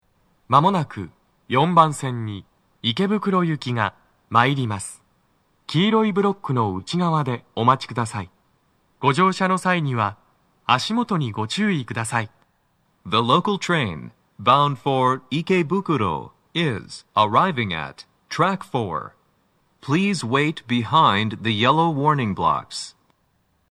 鳴動は、やや遅めです。
男声
接近放送1